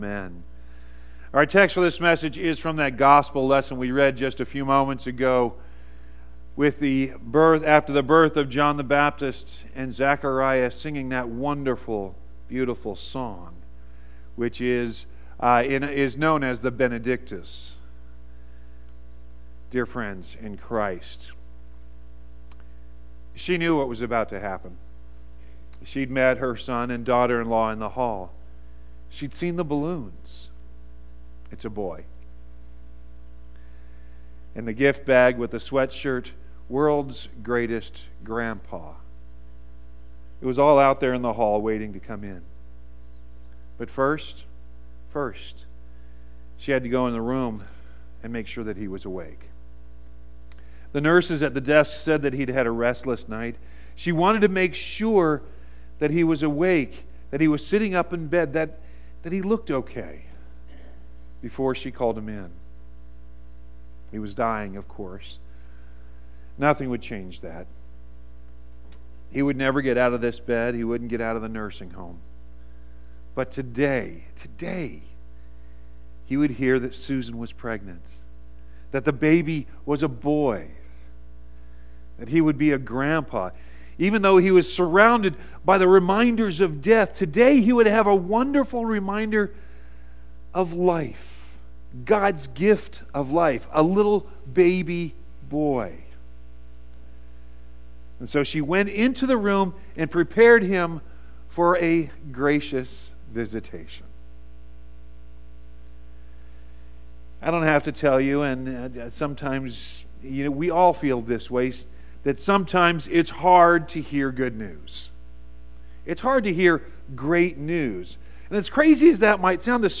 6-24-18-sermon.mp3